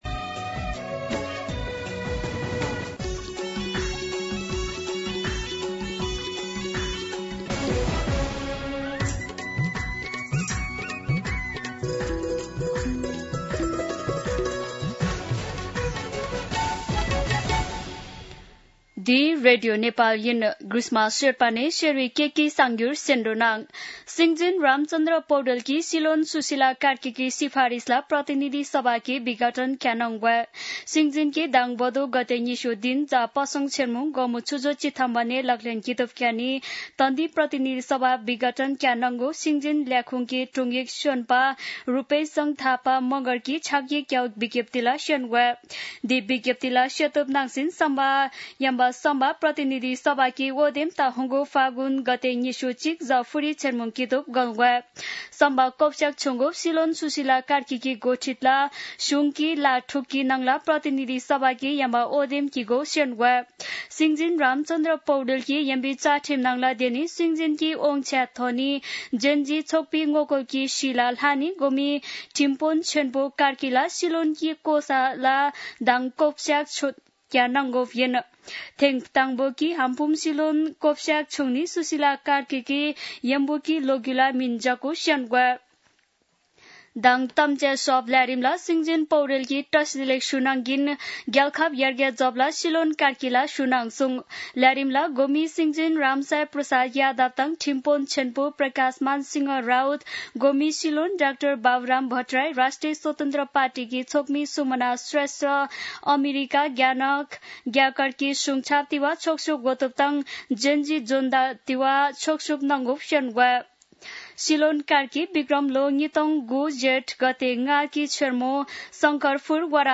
शेर्पा भाषाको समाचार : २८ भदौ , २०८२
Sherpa-News-5.mp3